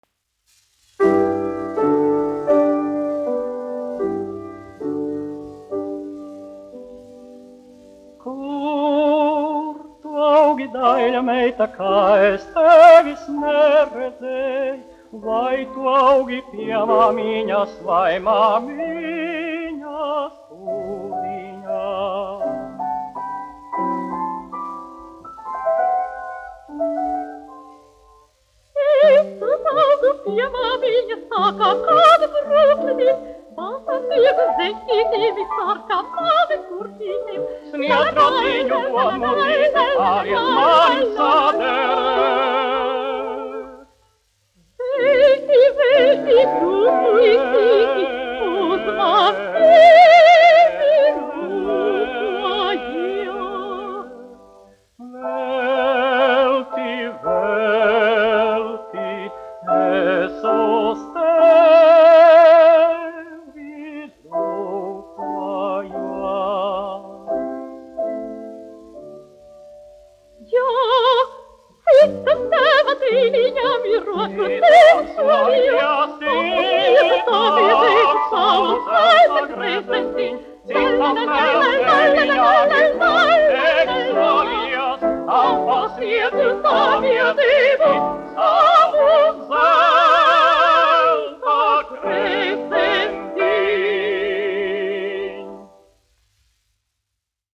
Kur tu augi, daiļa meita : latviešu tautas dziesma
1 skpl. : analogs, 78 apgr/min, mono ; 25 cm
Latvijas vēsturiskie šellaka skaņuplašu ieraksti (Kolekcija)